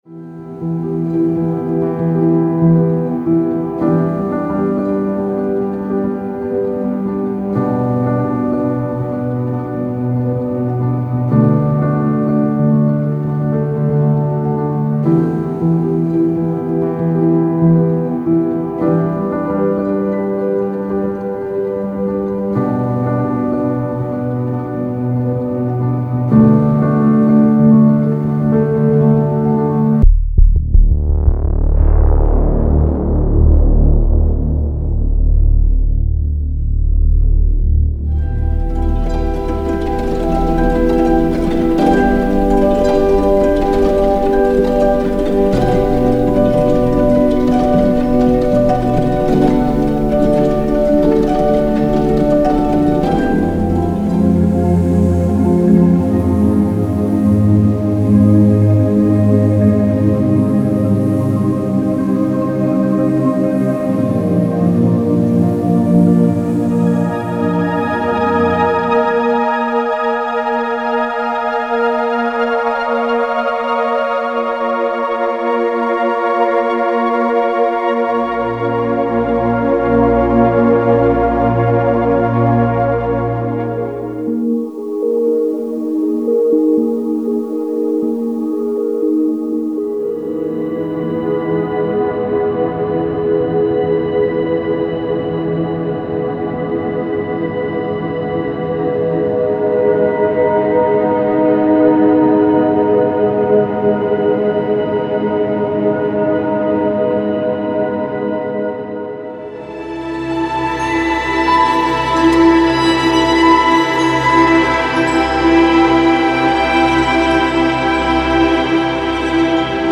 Ambient
Inside, you’ll find airy noise layers, abstract sound patterns, soft textures, and delicate ambient tones — perfect for Ambient, Chillout, Cinematic, and any genre where space, mood, and depth play a vital role.
These textures will help you build immersive atmospheres, enhance emotion, and give your music a professional, cinematic touch.